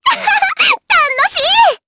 Dosarta-Vox_Skill4_jp.wav